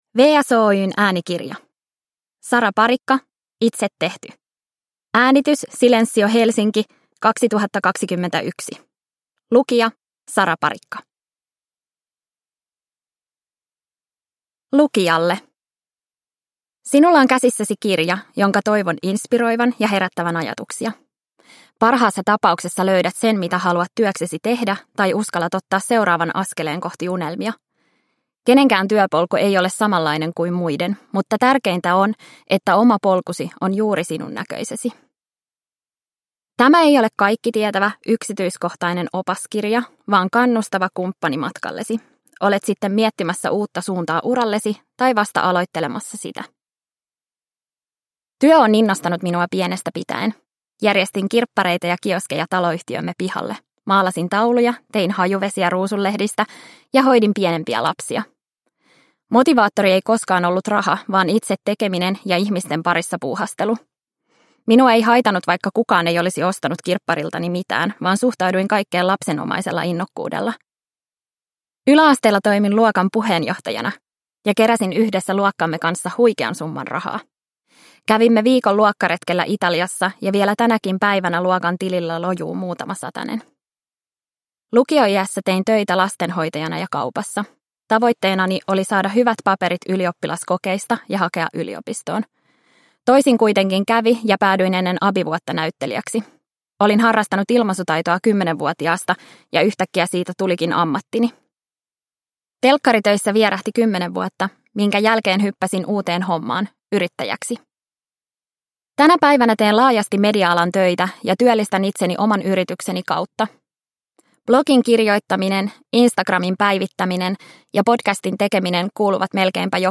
Itse tehty – Ljudbok